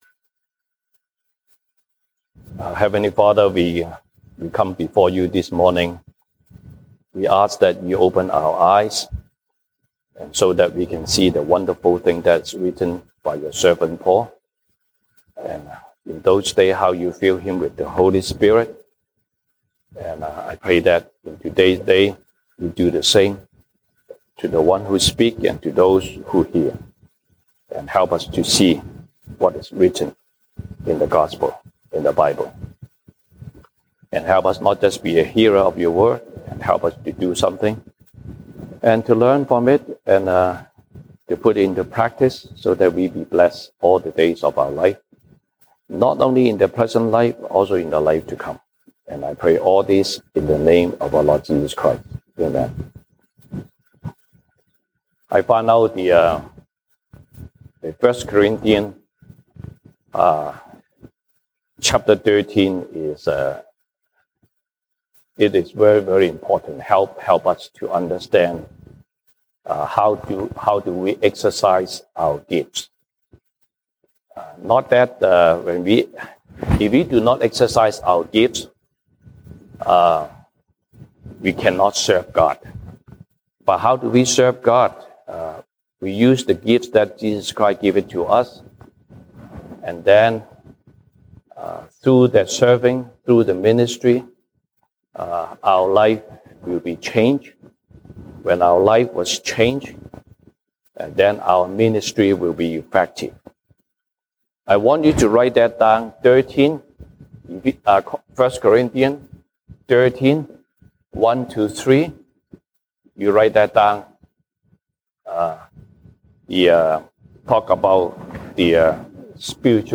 西堂證道 (英語) Sunday Service English: What is the most excellent way?
1 Corinthians Passage: 歌林多前書 1 Corinthians 13:1-4 Service Type: 西堂證道 (英語) Sunday Service English Topics